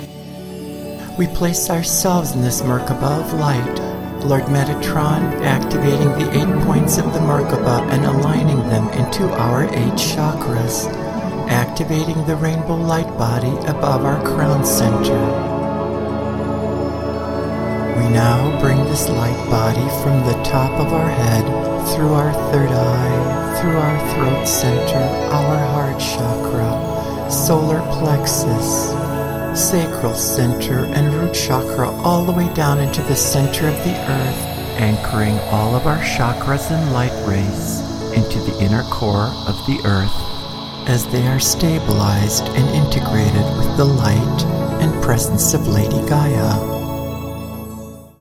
A dynamic guided Meditation to Empower Your Life.
With specially designed 432 Hz Solfeggio Frequencies to reactivate and heal our DNA for optimal health.
2.-Merkaba-InvocationSample.mp3